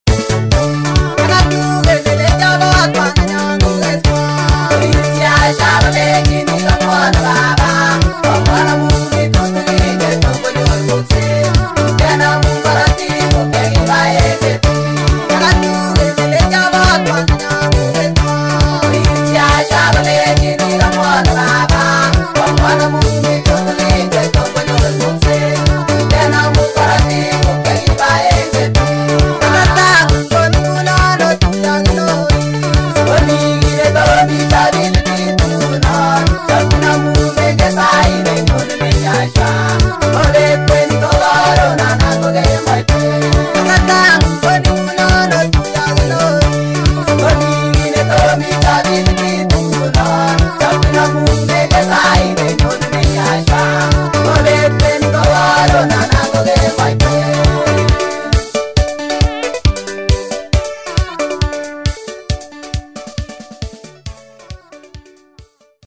Sabbath songs